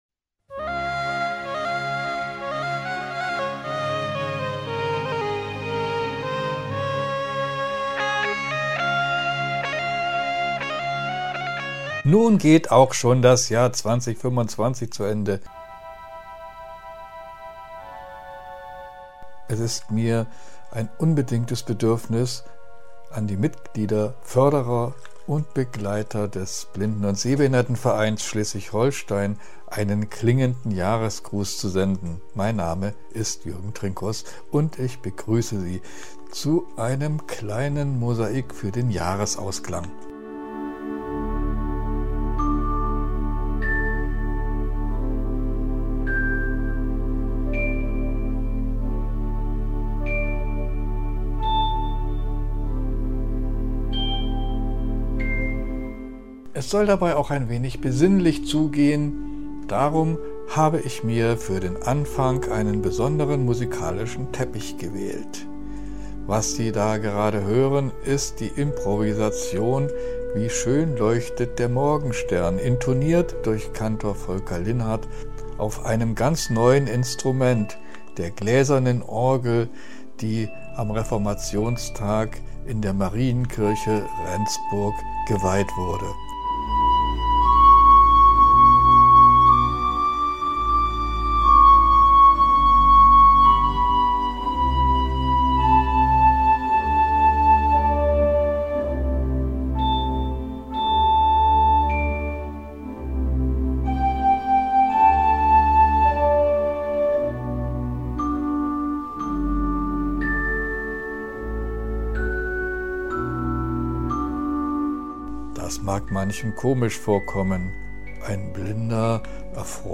Freut euch auf Stimmen aus dem Vorstand und der Geschäftsstelle, auf bewegende und inspirierende Momente sowie auf Highlights aus dem kulturellen Jahr 2025. Der Rückblick lädt zum Innehalten ein, zum Dankesagen – und zum Erinnern an viele gute Begegnungen, Projekte und Augenblicke.